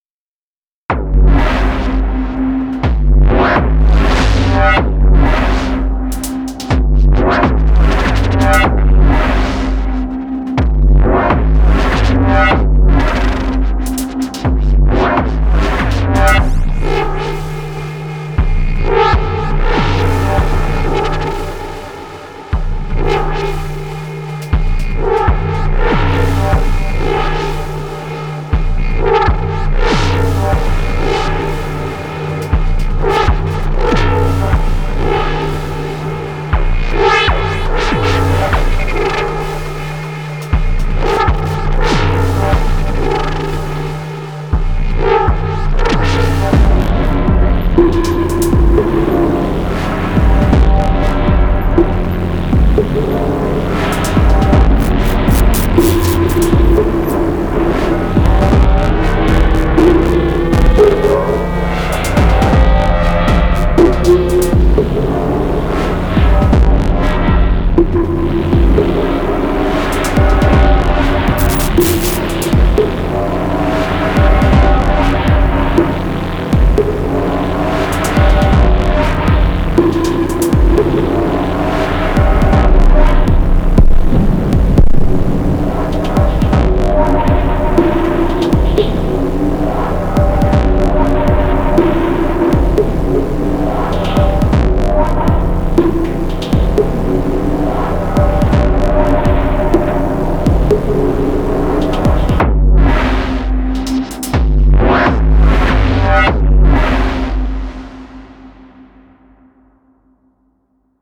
And here’s one, much more aggressive:)
This is 5 tracks, one is droning tone and the rest is percussion
I wanted to play with evolving drum sounds with ramp lfo on mod depth